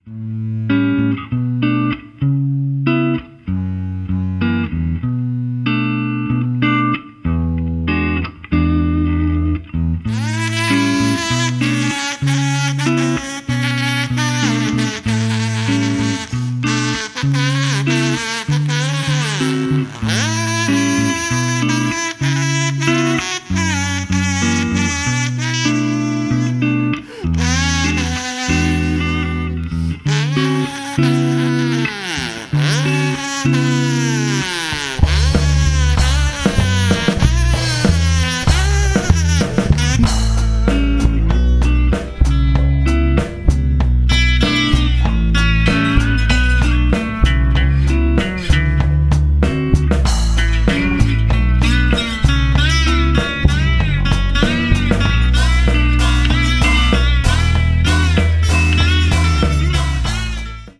(1:00) Some cool'n'jazzy ballad